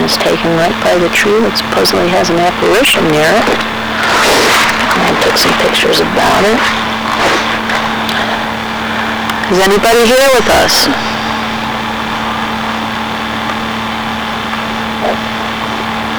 Electronic Voice Phenomena (EVPs) from Tabernacle Cemetery
These recordings were taken using a General Electric Microcassette Recorder, Model #3-5326A using a Radio Shack MC-60 Microcassette at normal speed.